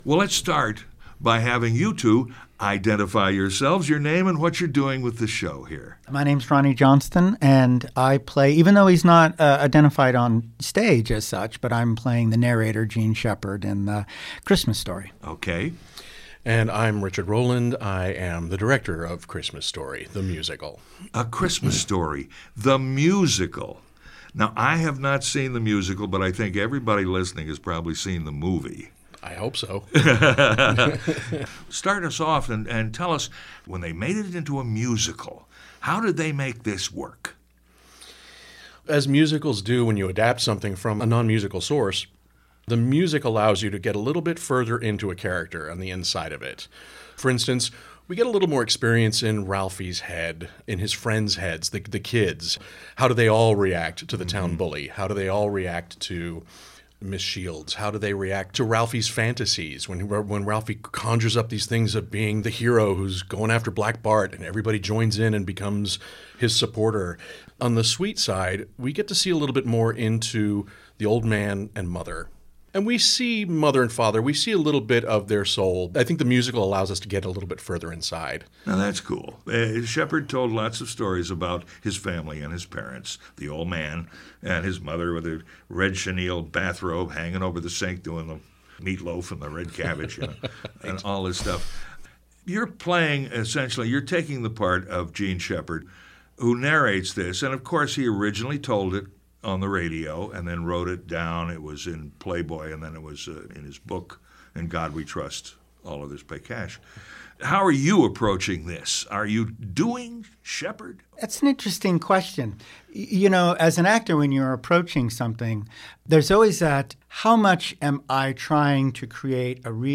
A Christmas Story Interview
WFHB-A-Christmas-Story-Radio-Interview.mp3